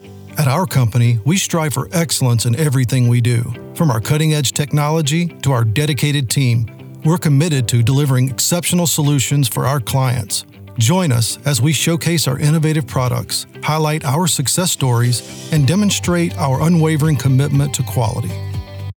Corporate Video VO
Corporate Video VO_Demo_1_0.mp3